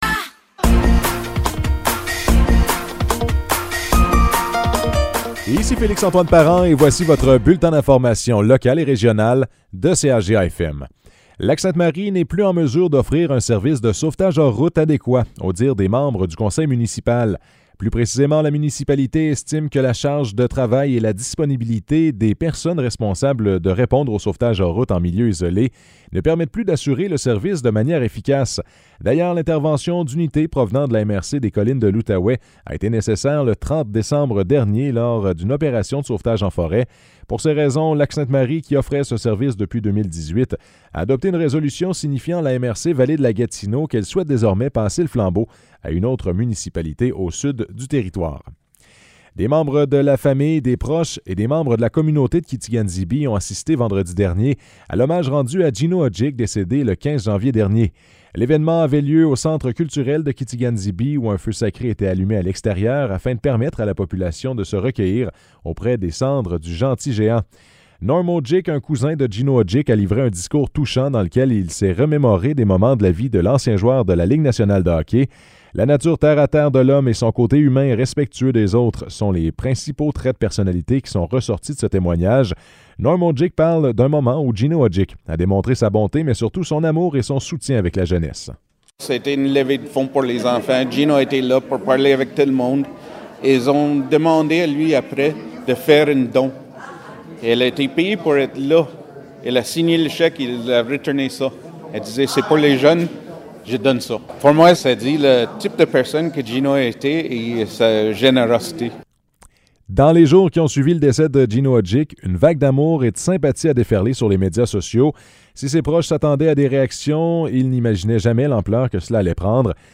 Nouvelles locales - 30 janvier 2023 - 15 h